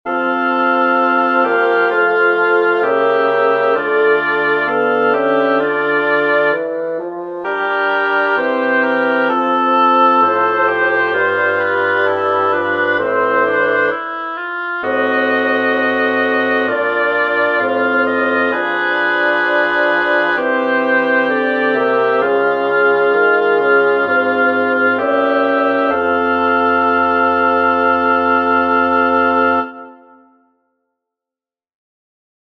Música antiga
A2-dictat-harmonic-antiga-audio-24-06.mp3